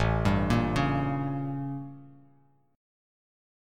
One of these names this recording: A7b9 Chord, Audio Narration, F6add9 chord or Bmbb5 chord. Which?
A7b9 Chord